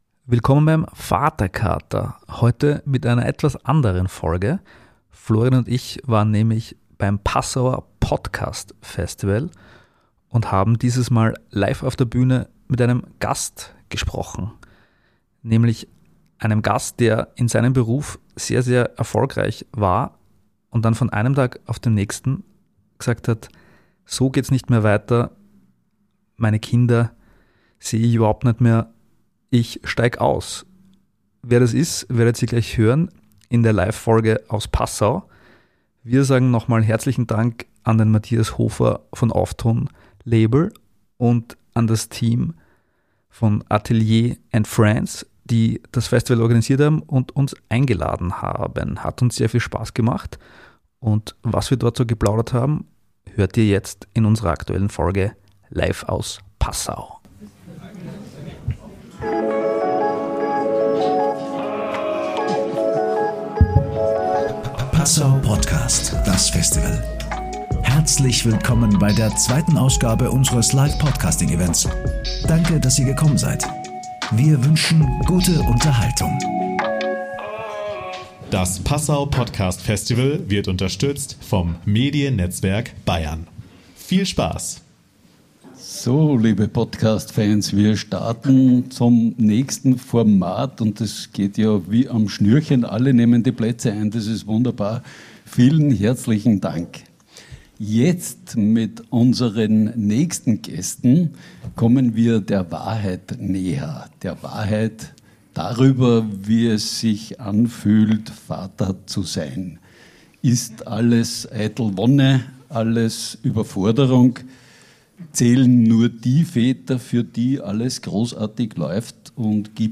Live aus Passau: Spitzenpolitiker und Vatersein geht sich nicht aus ~ Vaterkater Podcast